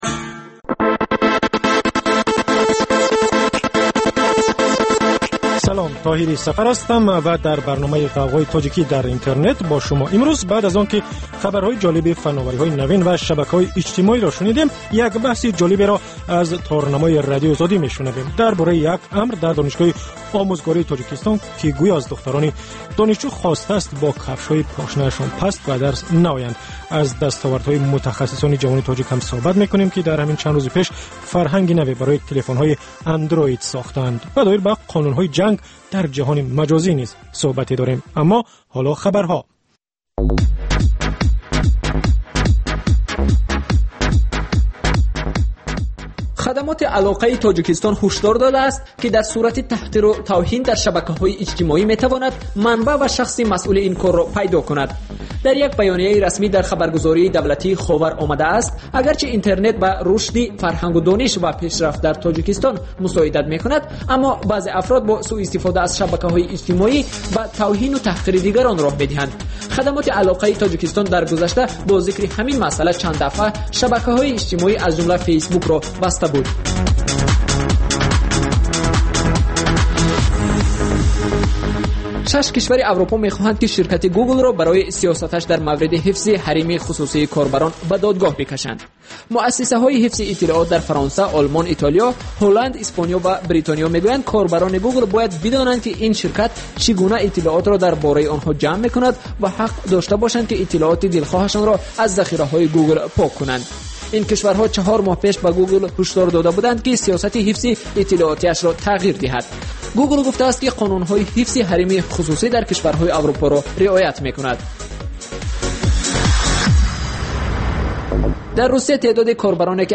Талоши чеҳранигорӣ аз афроди хабарсоз ва падидаҳои муҳими Тоҷикистон, минтақа ва ҷаҳон. Гуфтугӯ бо коршиносон.